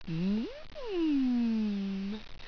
Index of /tactics/sfx/pain/sultry
mmmm.wav